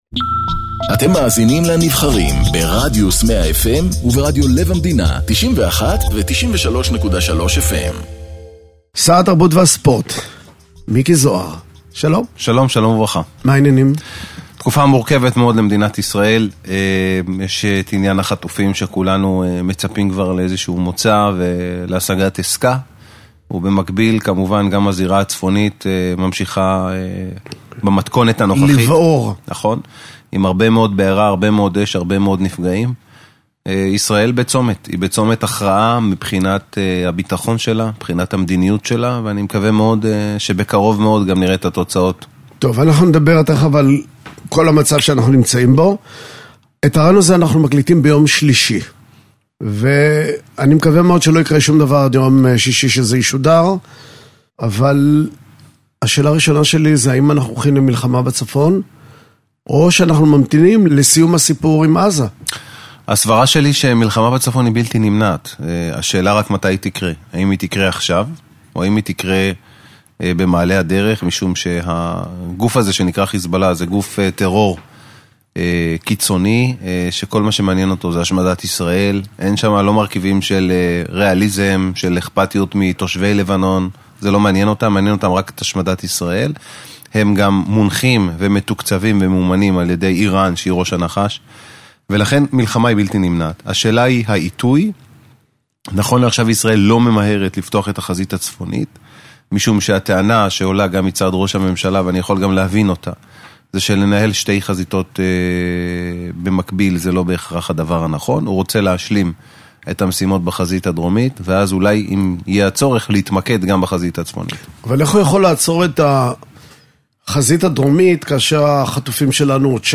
מראיין את שר התרבות והספורט, מיקי זוהר